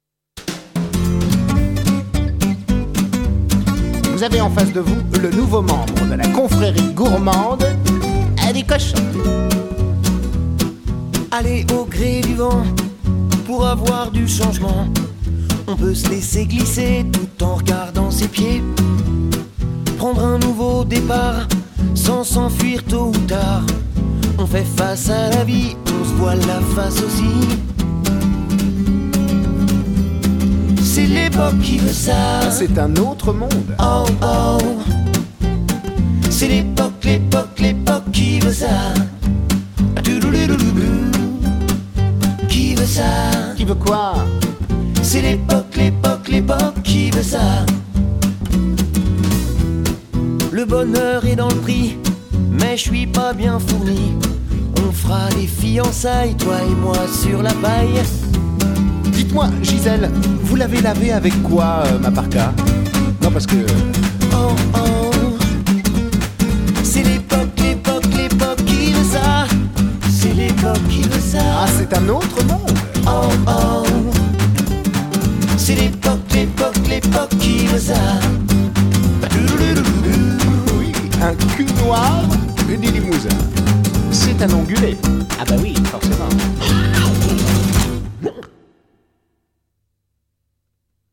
Ballade swing